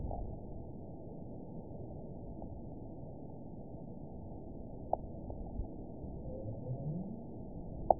event 917759 date 04/15/23 time 05:47:18 GMT (2 years ago) score 9.16 location TSS-AB01 detected by nrw target species NRW annotations +NRW Spectrogram: Frequency (kHz) vs. Time (s) audio not available .wav